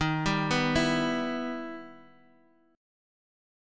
Em/Eb chord
E-Minor-Eb-x,6,5,4,5,x-8.m4a